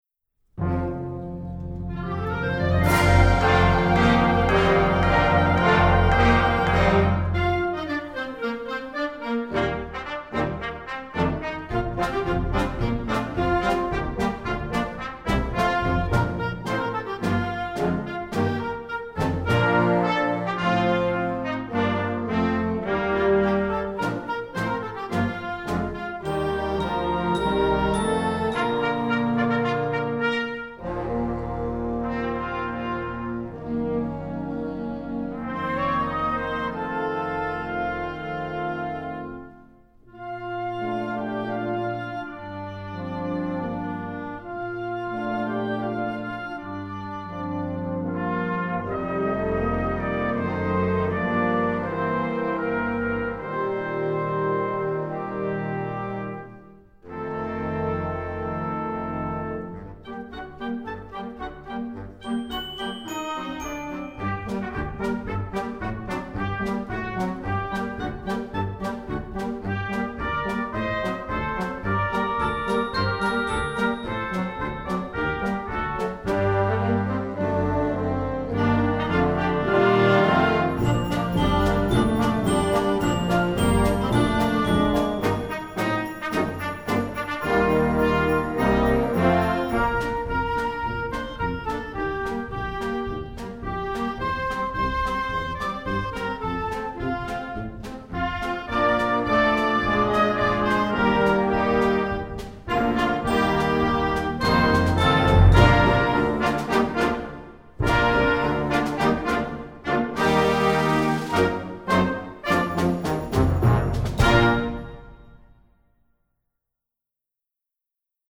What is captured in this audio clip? sacred, secular